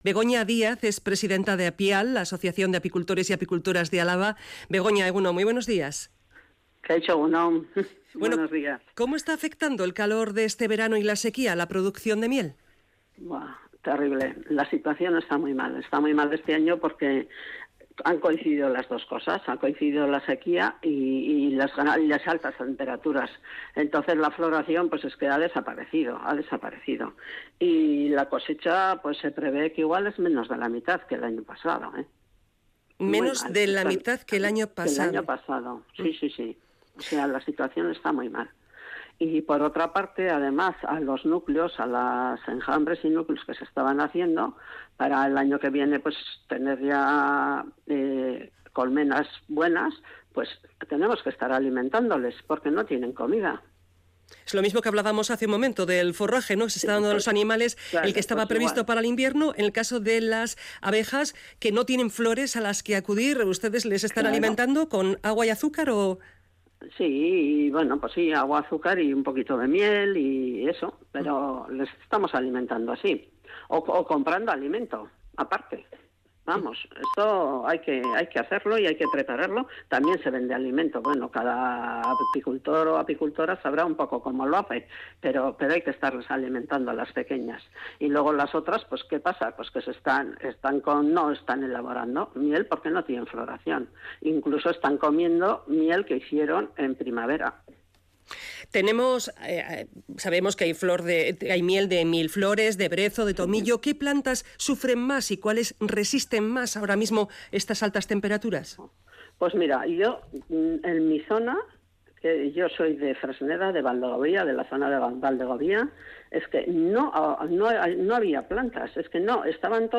Entrevistada en Radio Vitoria